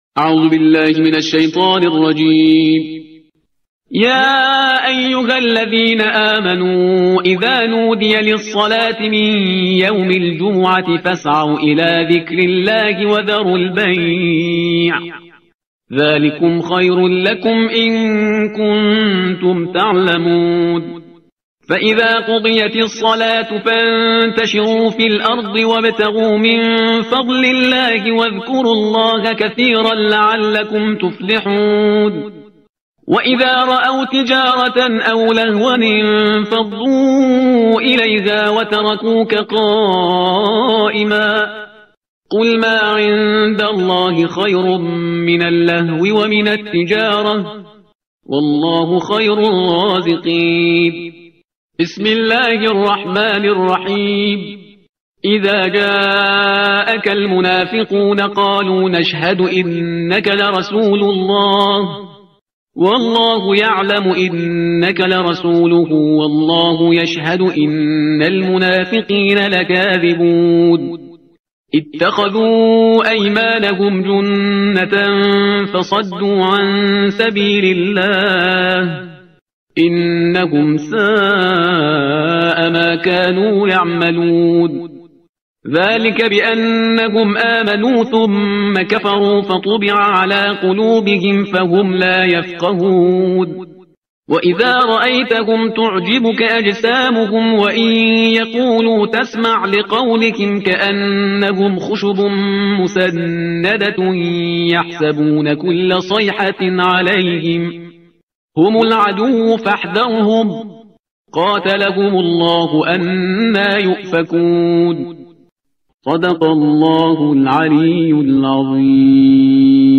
ترتیل صفحه 554 قرآن – جزء بیست و هشتم